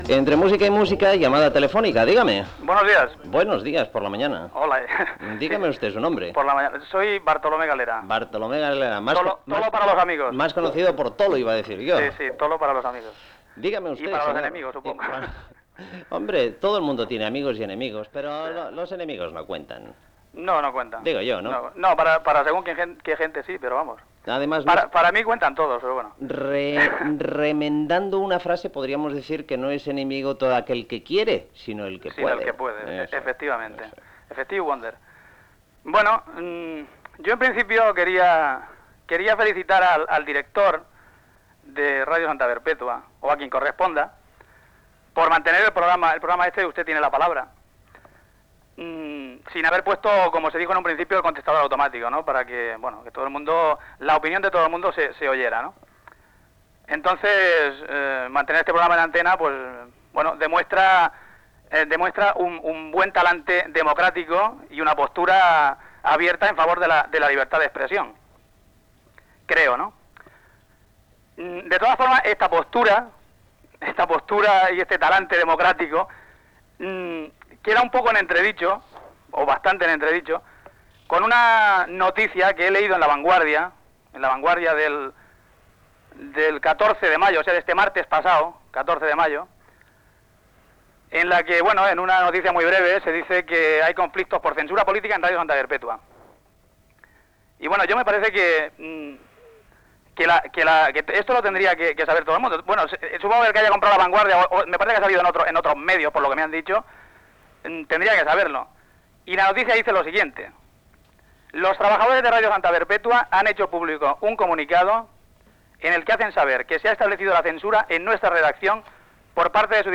Trucada d'un oïdor que es felicita per la continuïtat de "Usted tiene la palabra", llegeix una informació de "La Vanguardia" que informa del conflicte per censura política a Ràdio Santa Perpétua i comenta un llibre sobre el bosc de Can Soldevila
FM